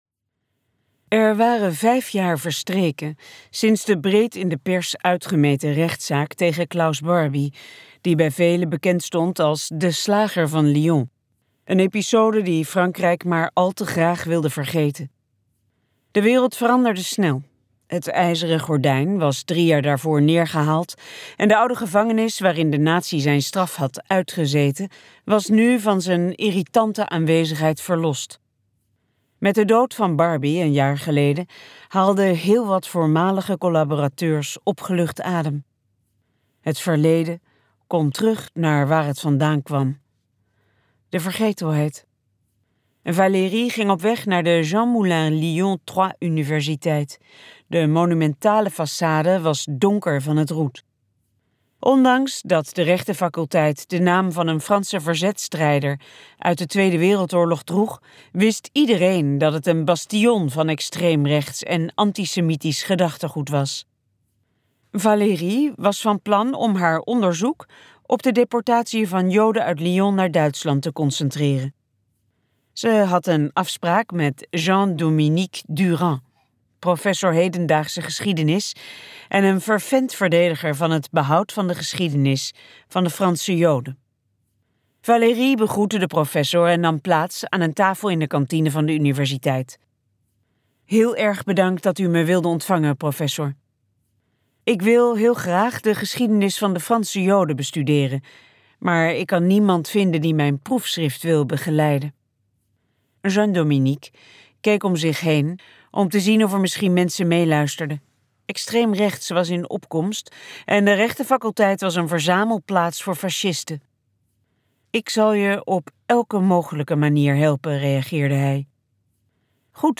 KokBoekencentrum | De vergeten namen luisterboek